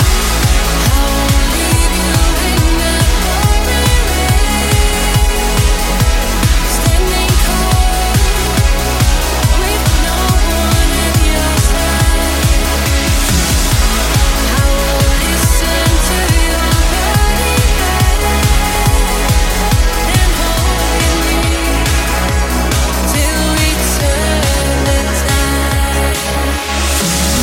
Genere: uplifting trance, (preascolto a 140bpm)